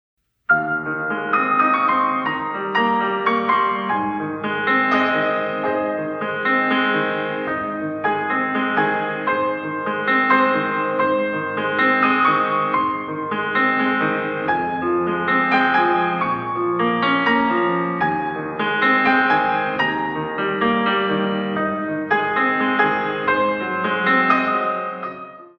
In 3
64 Counts